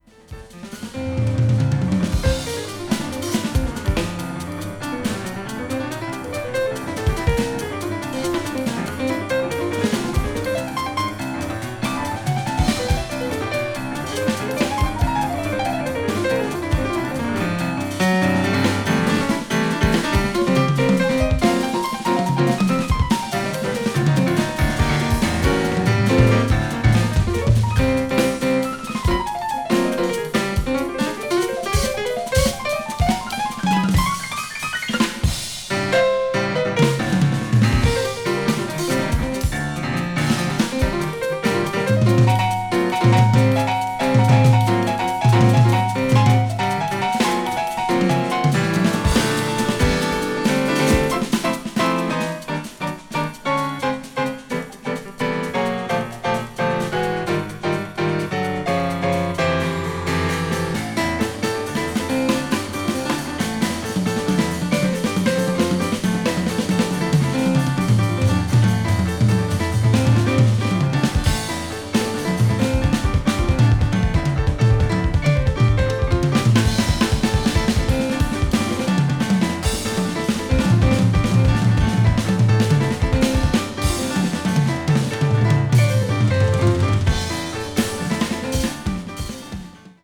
media : EX/EX(わずかにチリノイズが入る箇所あり)
crossover   ethnic jazz   fusion   jazz groove   latin jazz